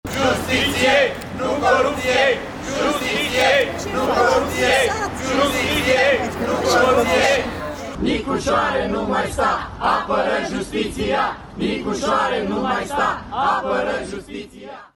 În fața Ambasadei, un grup de români veniți cu pancarte i-au cerut lui Nicușor Dan să apere justiția.
Românii au scandat: „Justiție, nu corupție!” și „Nicușoare, nu mai sta, apără justiția!”
17dec-07-ambianta-scandari-in-fata-ambasadei.mp3